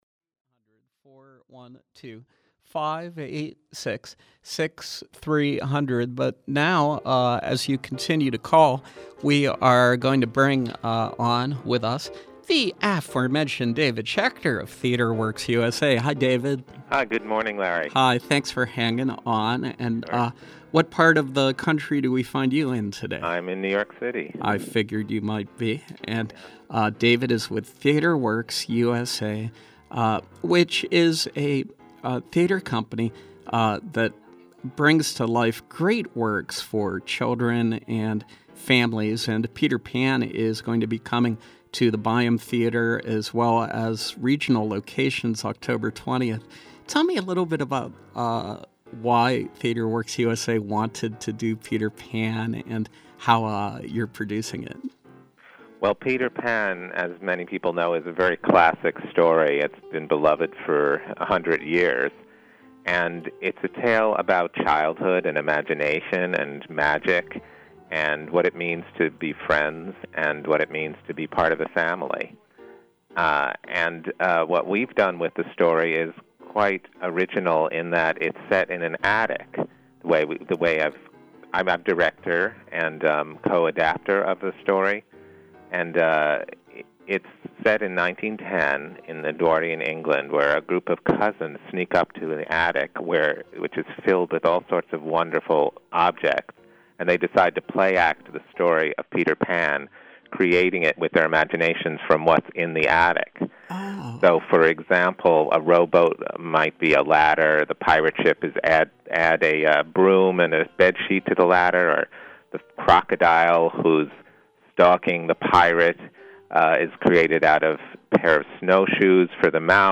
Home » Callers, Interviews